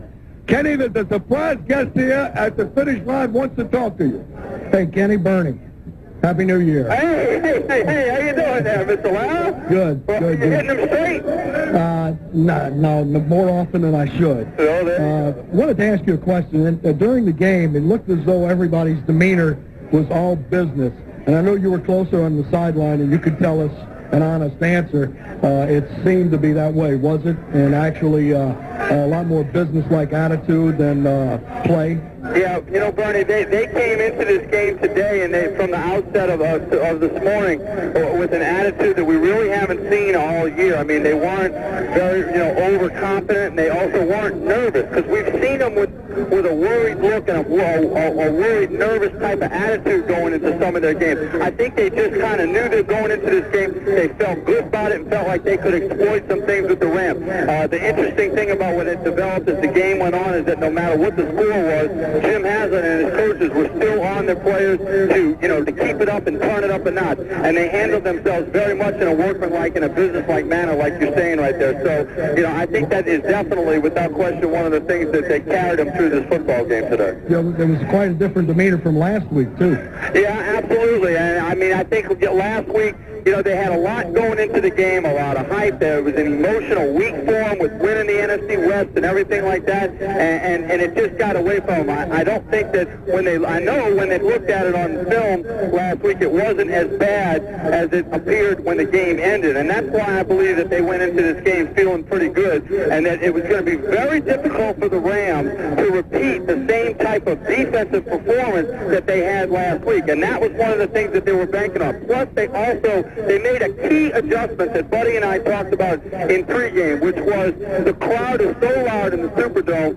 Aircheck   December 30, 2000  (2:59)